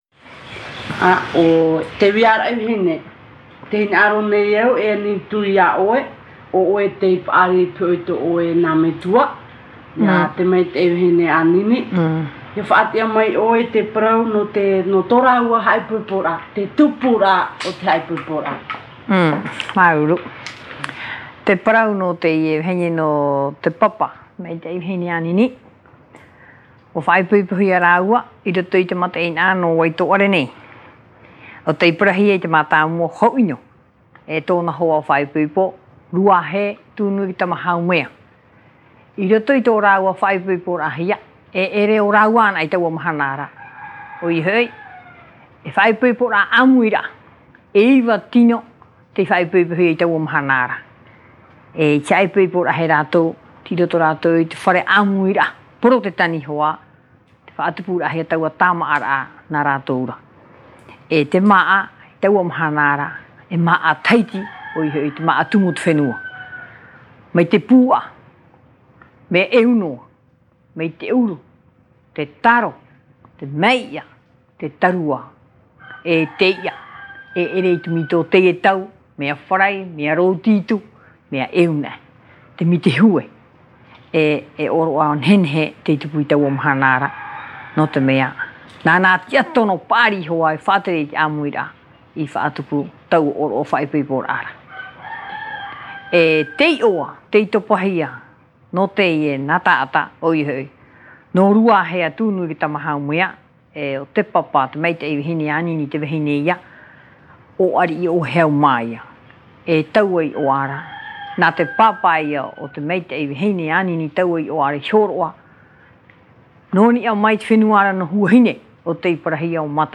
Te ta’ata fa’ati’a / Récit